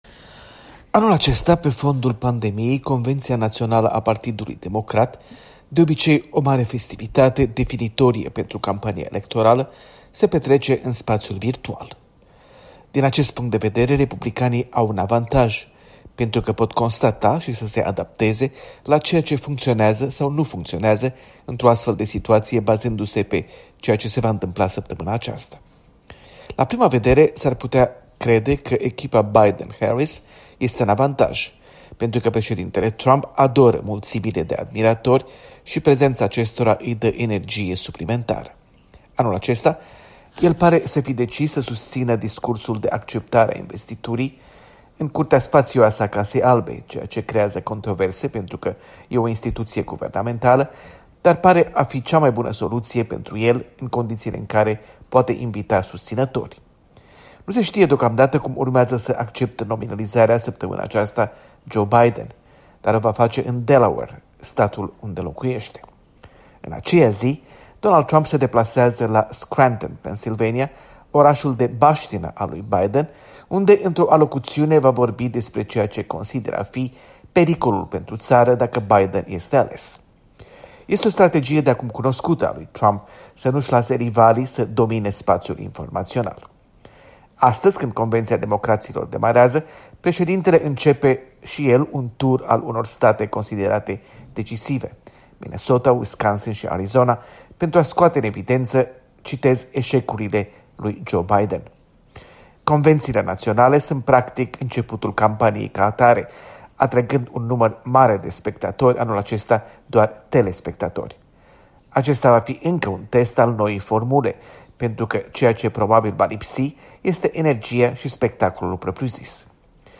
Corespondența zilei de la Washington